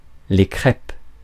Ääntäminen
Ääntäminen France (Paris): IPA: [le kʁɛp] France (Paris): IPA: /kʁɛp/ Haettu sana löytyi näillä lähdekielillä: ranska Käännöksiä ei löytynyt valitulle kohdekielelle. Crêpes on sanan crêpe monikko.